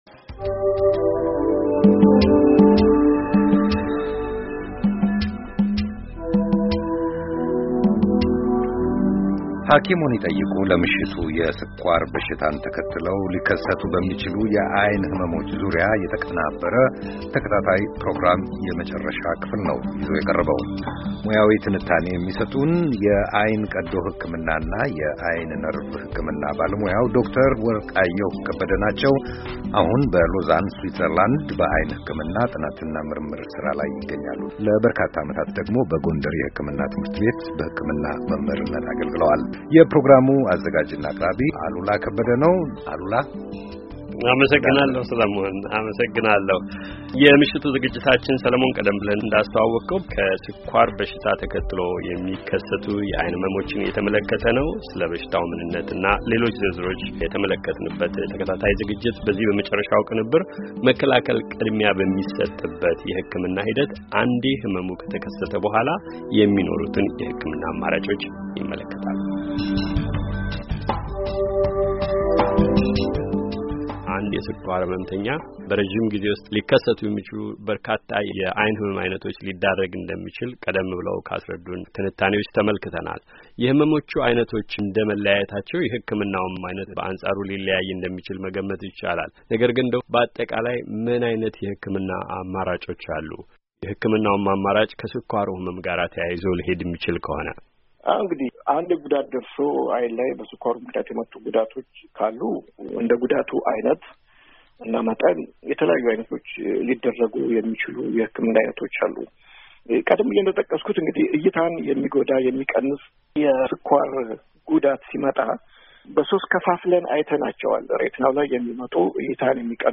የሕክምናው አማራጮች? የቃለ ምልልሱን የመጨረሻ ክፍል ከዚህ ያድምጡ፤